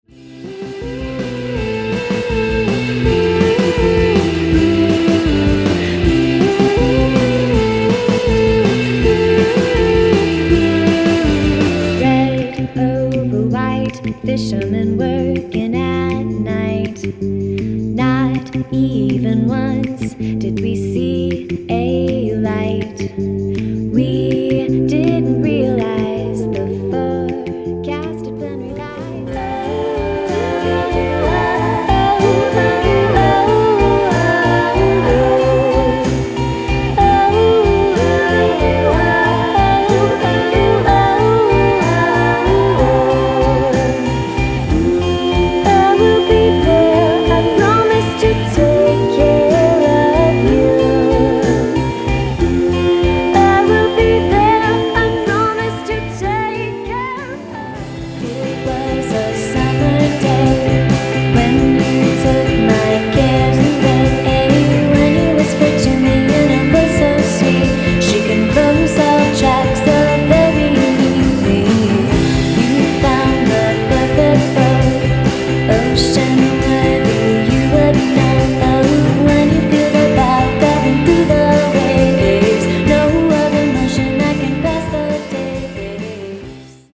Genre/Style: Pop/inde